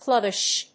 clubbish.mp3